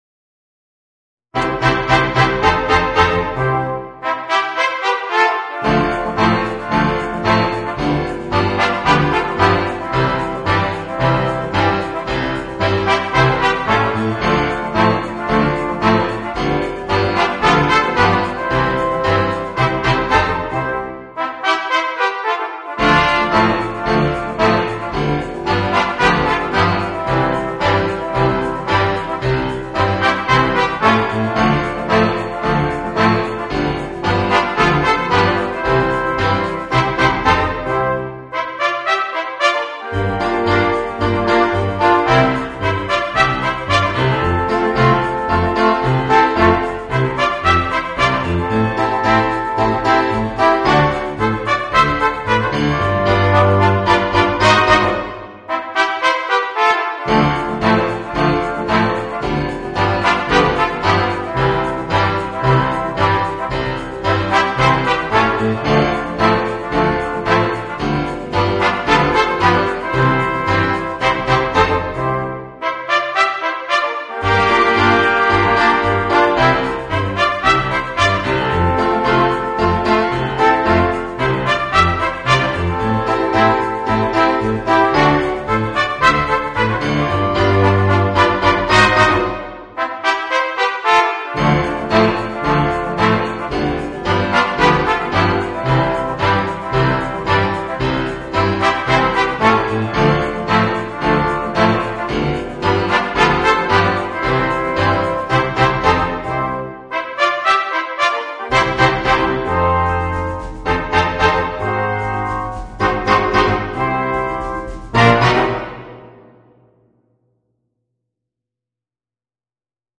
Voicing: 3 Trumpets, Horn and Trombone